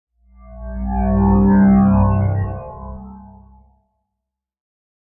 Spacecraft Satellite Sound Effect
This satellite passing sound effect captures the smooth motion of a satellite or space probe gliding through orbit. It creates a futuristic atmosphere ideal for sci-fi videos, games, and space projects.
Spacecraft-satellite-sound-effect.mp3